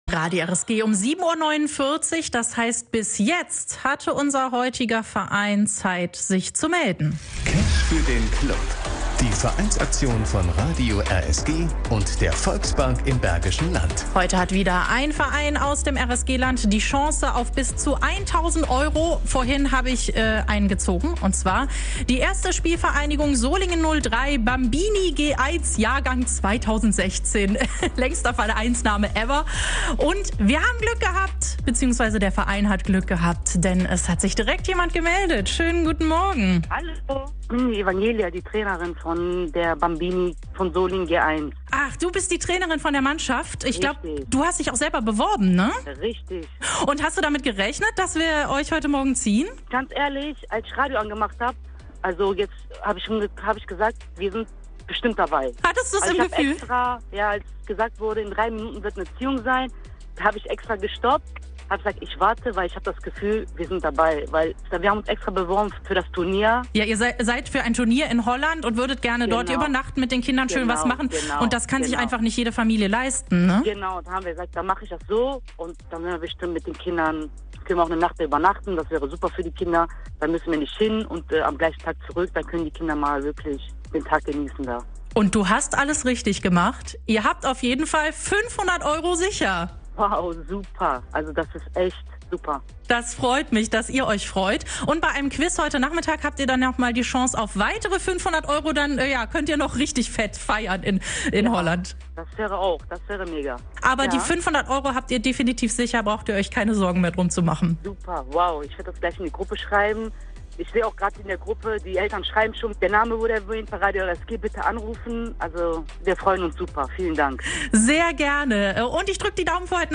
Cash für den Club 2023Spvg. Solingen 03 - Ziehung
cash-01-spvg-solingen---anruf.mp3